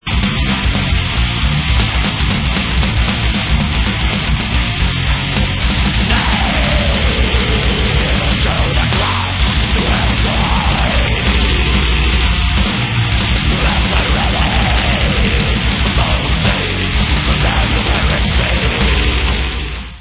sledovat novinky v oddělení Black Metal